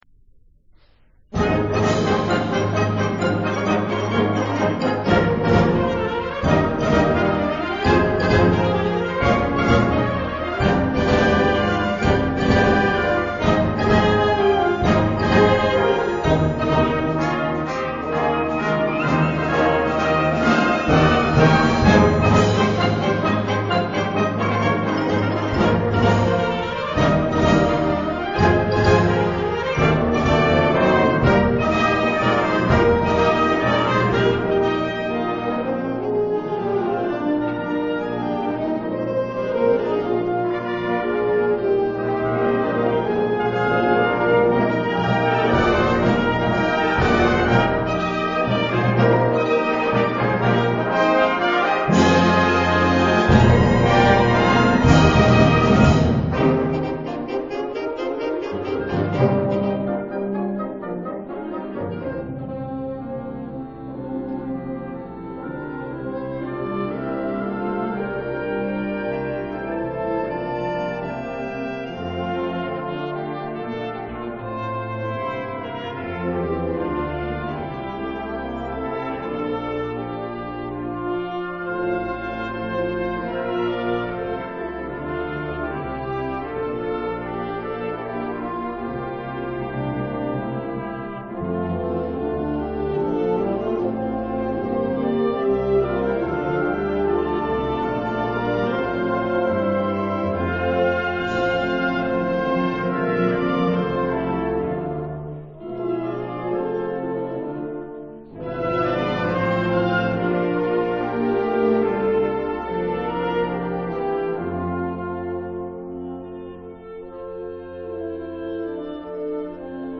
“04 ゴールデン・ジュビリー” (文京ウインドオーケストラ 「27回定期」より) トラック4。